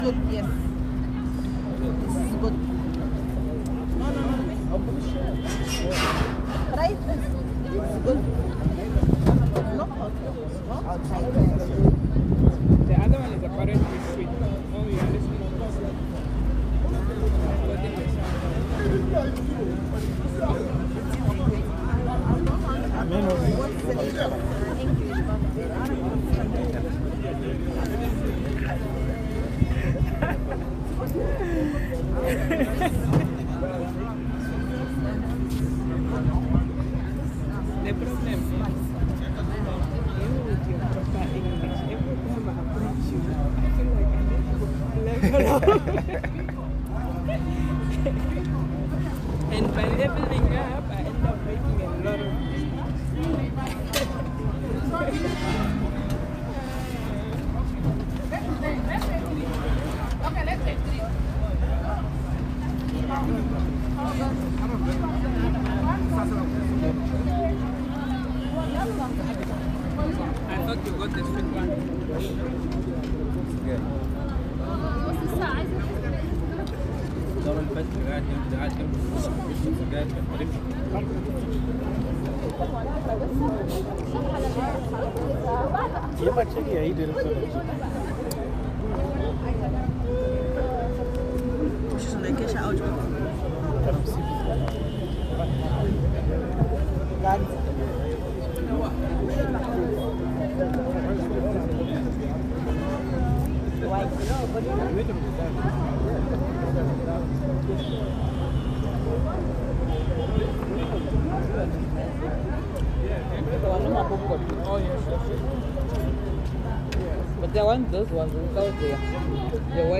In Cairo, the pace was varied, stop-start like the traffic. It was teeming with life as the locals ebbed and flowed about their business—moving like people with somewhere to be, and then they stopped for prayer hour.
Even at night when I looked out of the window of the hotel lounge from the third floor, I could hear the city, perpetual motion on the roads, the bleating sound of car horns turning into a pulse that beat continuously.